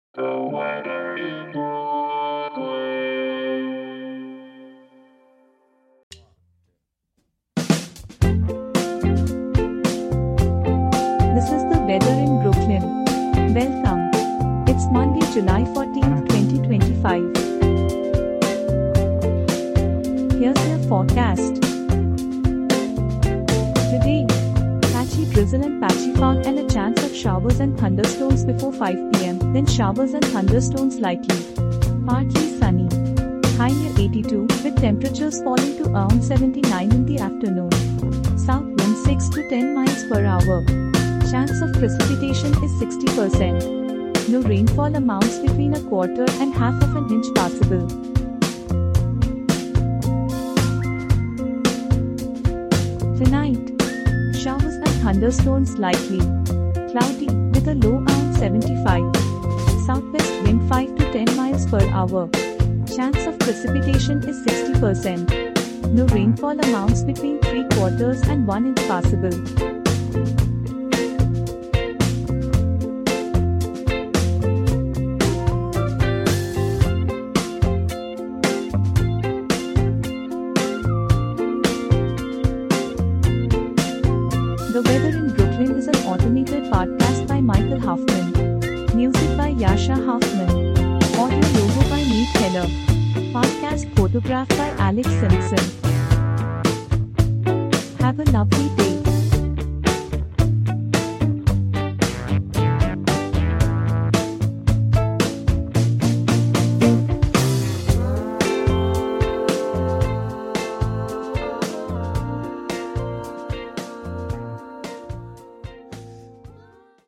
and is generated automatically.